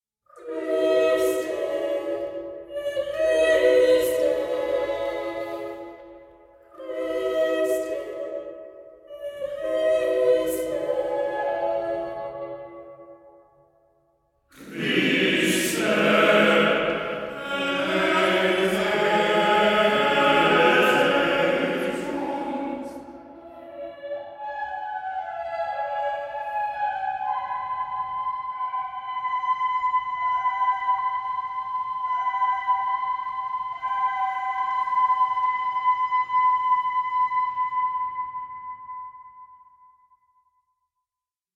rich, intense and finely structured music